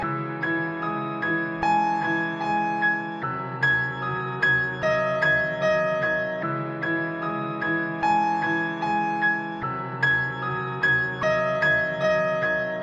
令人毛骨悚然的声乐氛围
Tag: 氛围 出没 fantasma的 爬行 万圣节 恐怖 恶魔 可怕